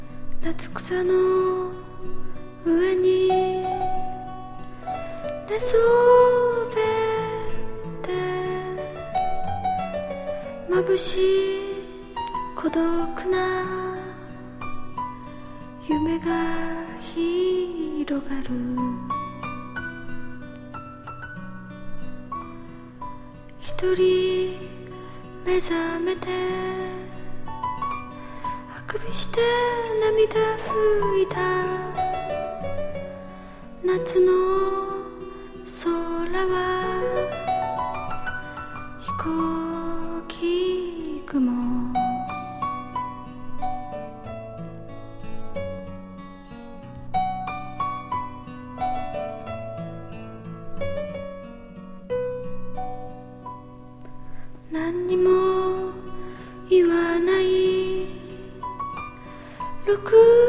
ストリングスを取り入れた’77年3rdアルバム。先鋭ウィスパー・フォーク